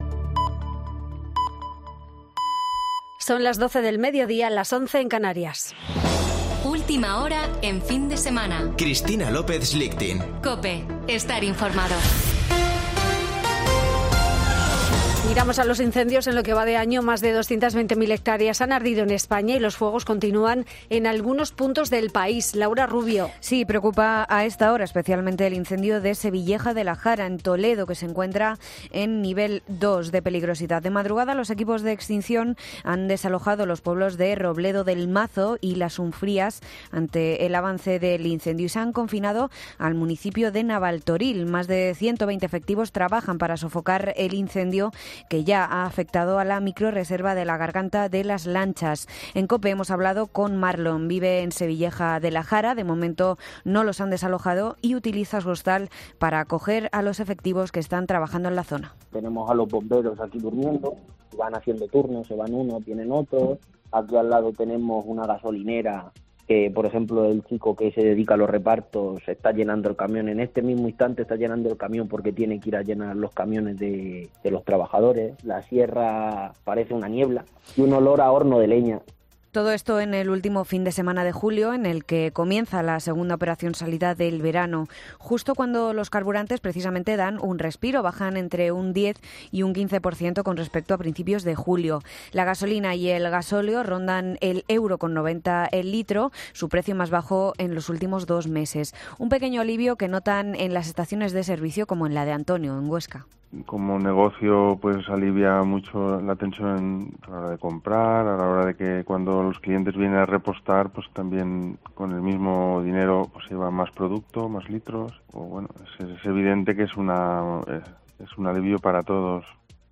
Boletín de noticias de COPE del 30 de julio de 2022 a las 12:00 horas